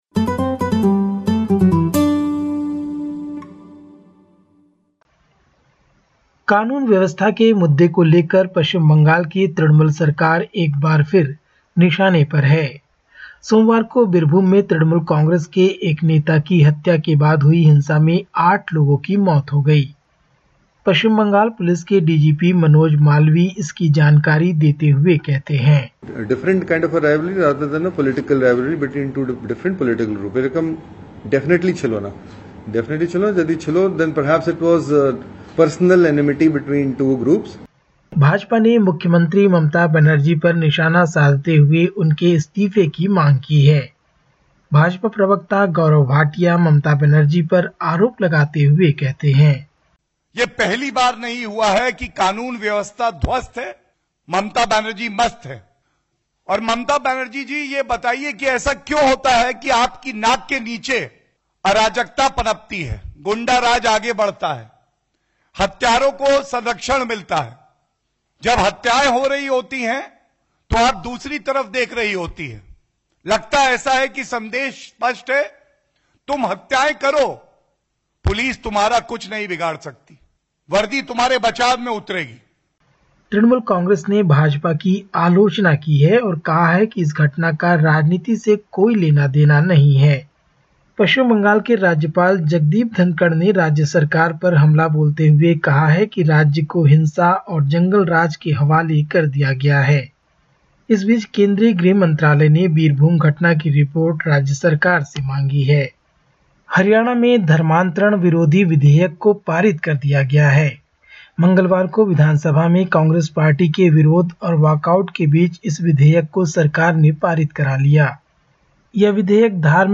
Listen to the latest SBS Hindi report from India. 23/03/2022